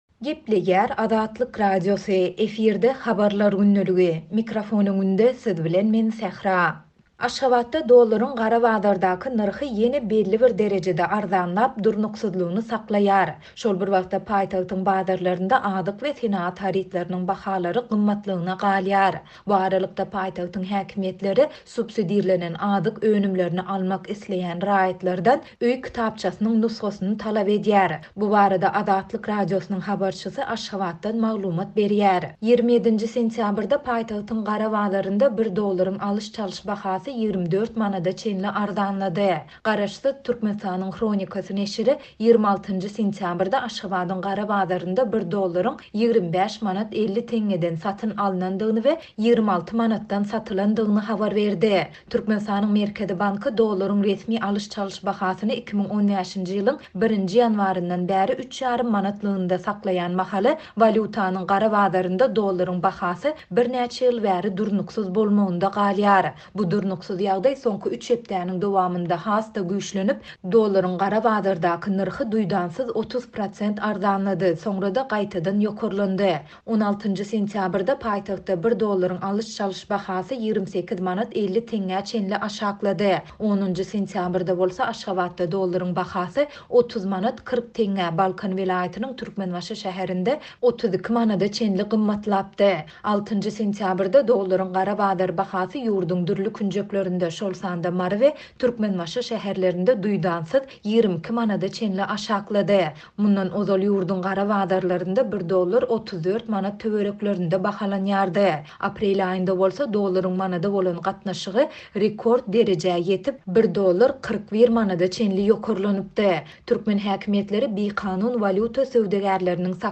Bu barada Azatlyk Radiosynyň habarçysy Aşgabatdan maglumat berýär.